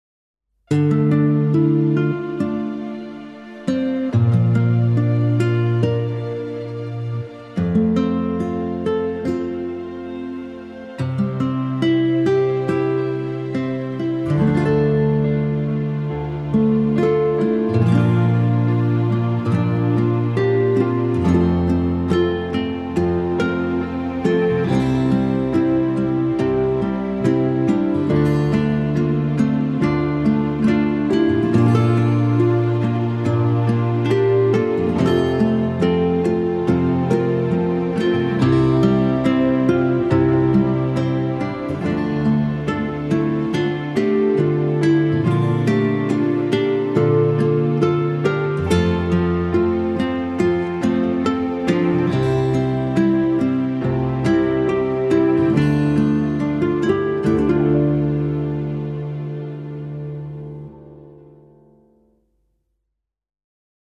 piano - intimiste - romantique - aerien - melodieux